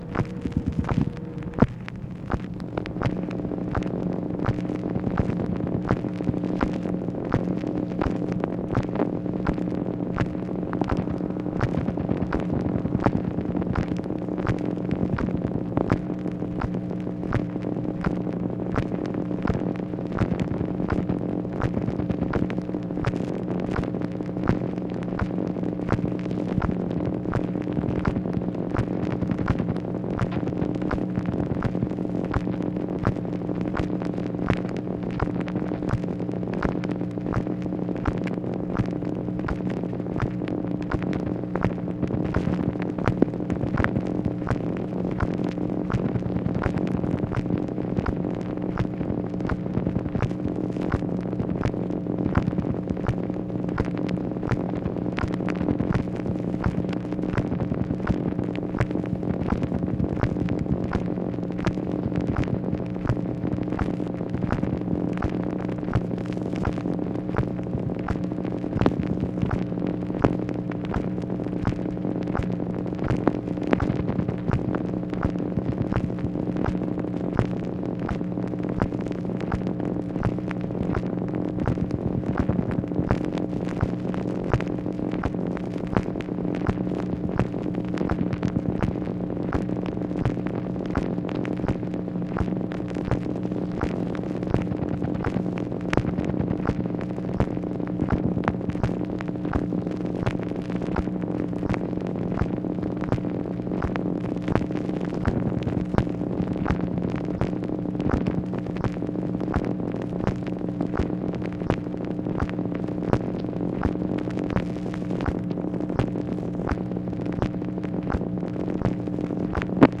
MACHINE NOISE, June 24, 1965
Secret White House Tapes